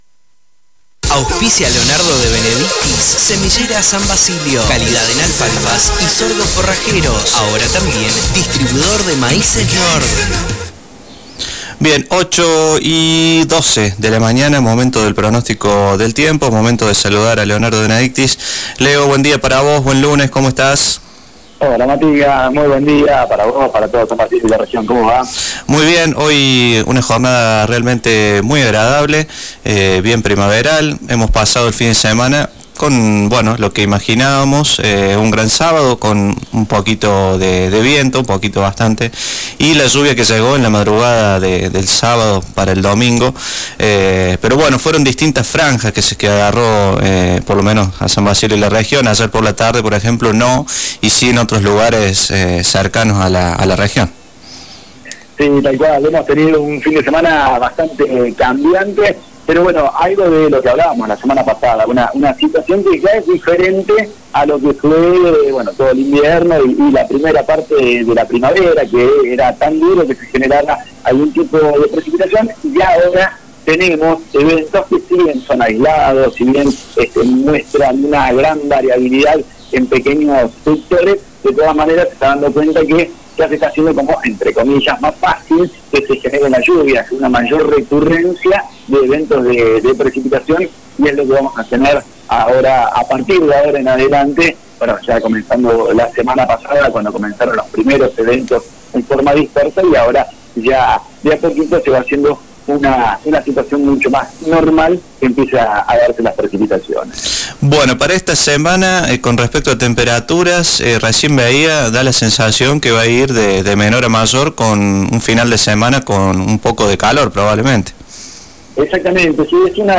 El pronóstico del tiempo: Lo que se espera para la semana - FM Samba